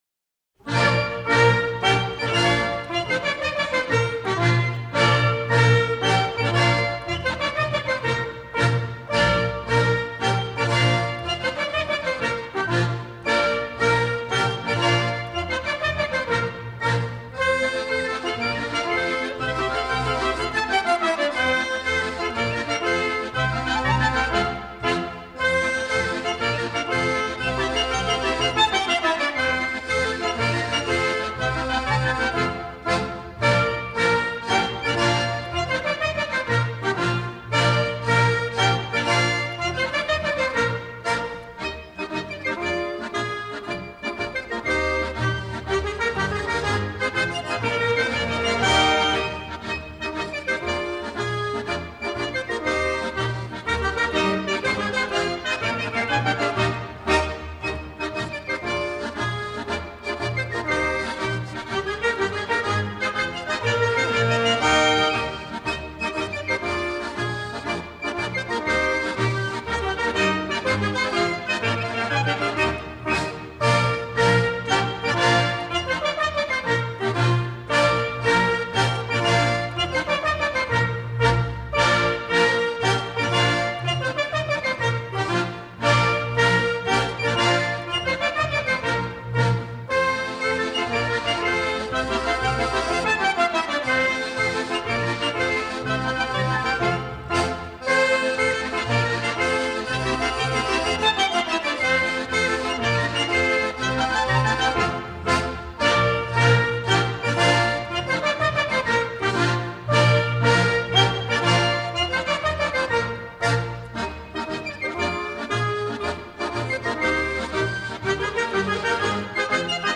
Musique d'Huémoz: Rebaille m'ein me (Montferrine)